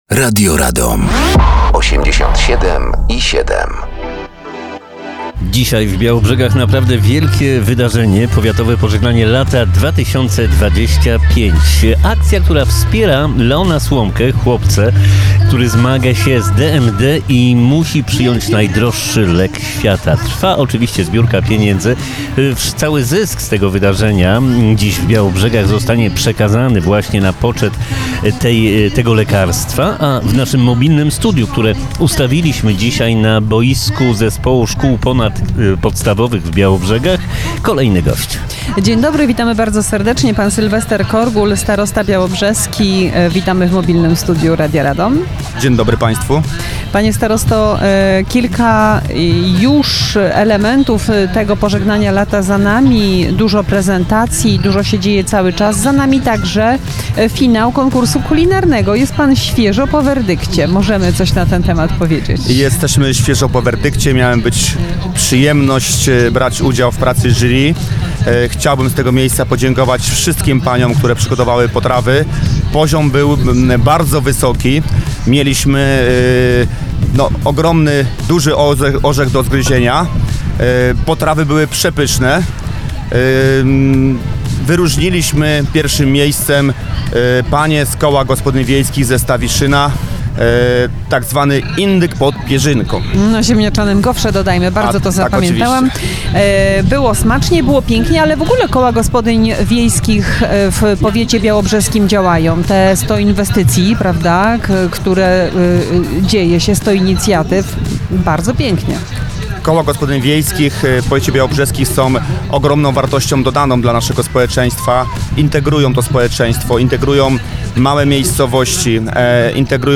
Dziś Białobrzegach Powiatowe Pożegnanie Lata 2025 oraz mobilne Studio Radia Radom.
Naszym gościem był Starosta Białobrzeski Sylwester Korgul